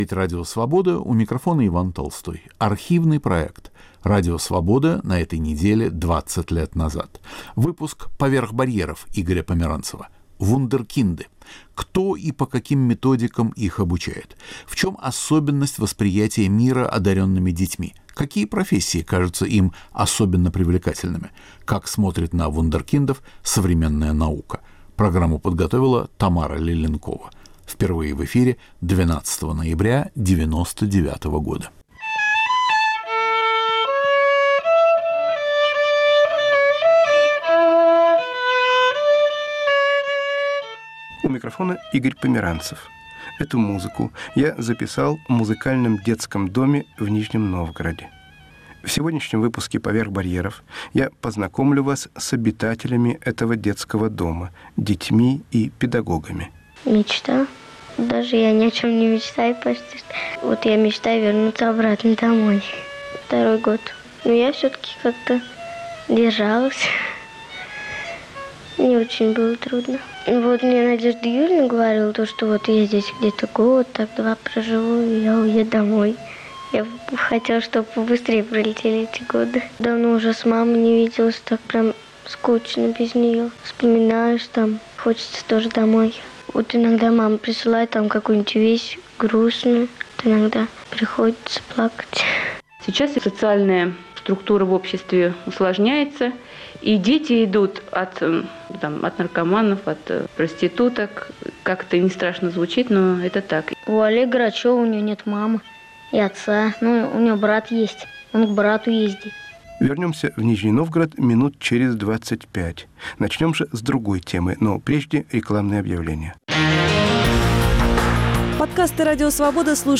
Архивный проект. Иван Толстой выбирает из нашего эфира по-прежнему актуальное и оказавшееся вечным.